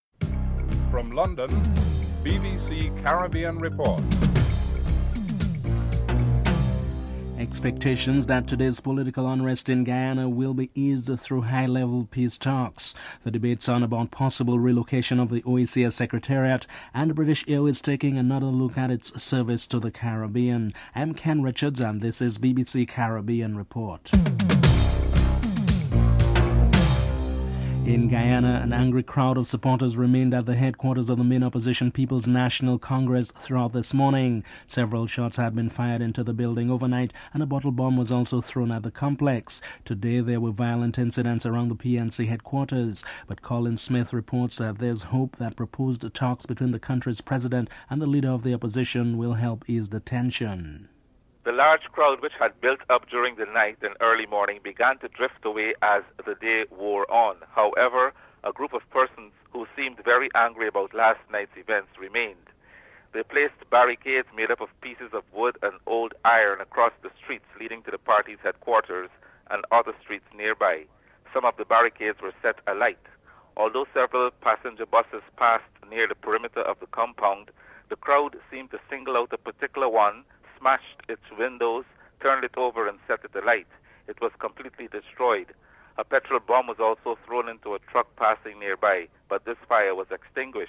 1. Headlines (00:00-00:27)
6. British diplomat Tony Longrigg who was until recently posted in Moscow is the new governor designate of Montserrat. Tony Longrigg is interviewed (10:47-13:39)